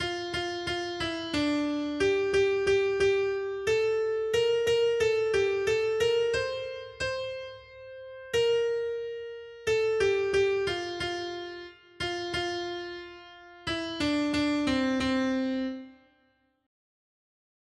Noty Štítky, zpěvníky ol101.pdf responsoriální žalm Žaltář (Olejník) 101 Skrýt akordy R: Pamatuj na nás, Hospodine, pro náklonnost k svému lidu. 1.